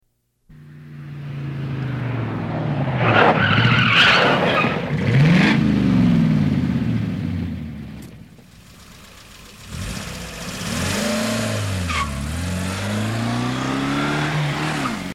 3D звук , слушать только в наушниках (иначе эффекта ни будет ) , желательно в компьютерных , итак включаем , закрываем глаза , расслабляемся , слушаем !
Avto.mp3